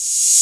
Open Hats
MURDA_HAT_OPEN_REVERSE.wav